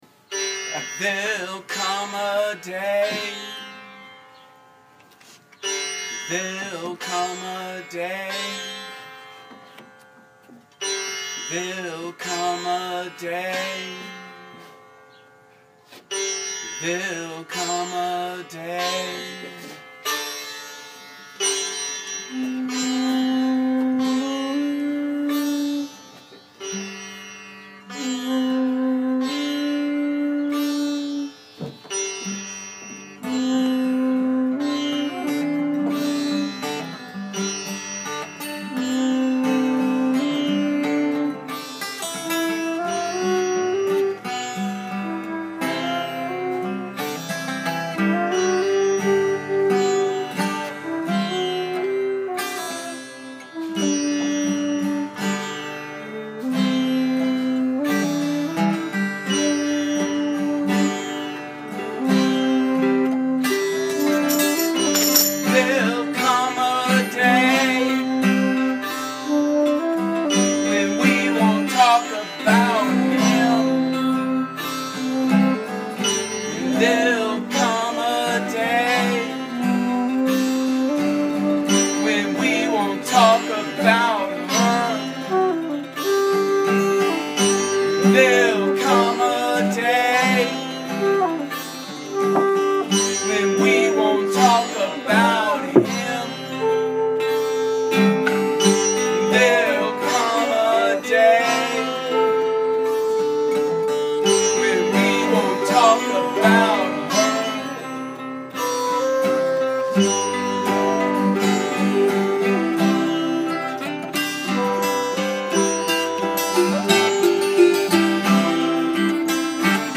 ALL MUSIC IS IMPROVISED ON SITE
acoustic)guitar/voice
sitar/voice
alto flute
Bongos